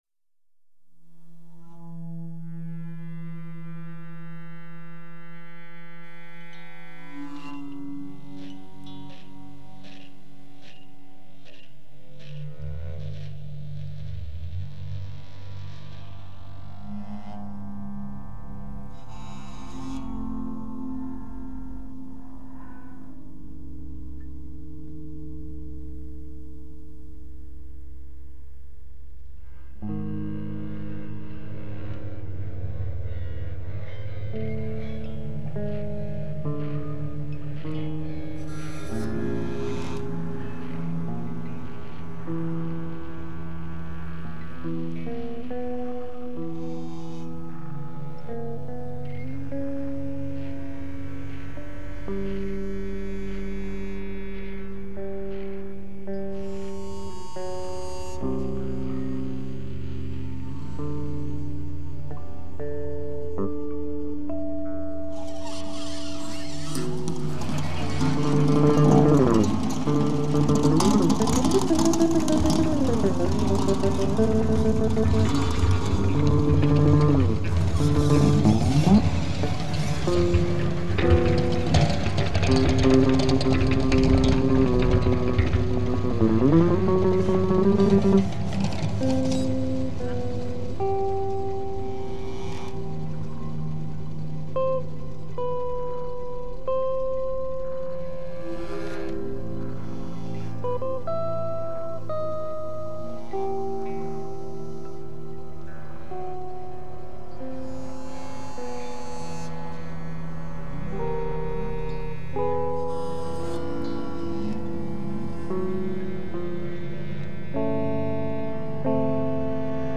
guitar and electroncis Recorded and filmed in Denton, TX.